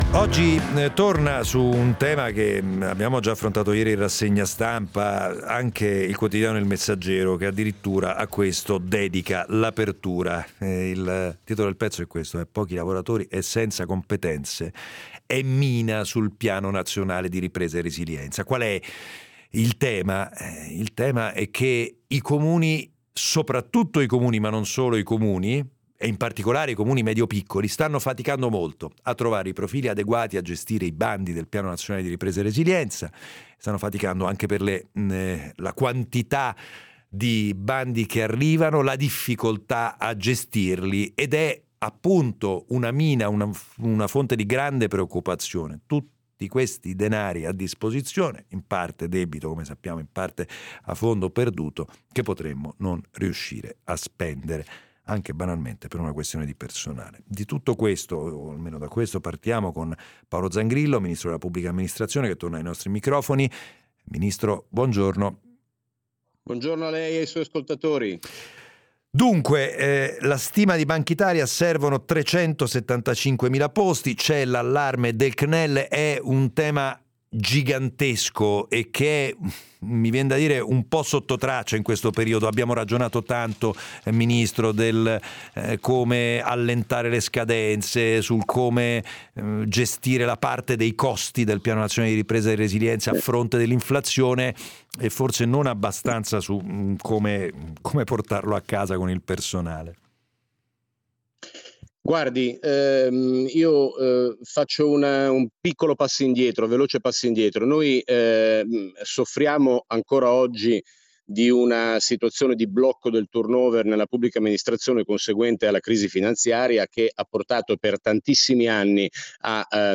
L'intervento del Ministro per la pubblica amministrazione, Paolo Zangrillo, nel corso della trasmissione 24 Mattino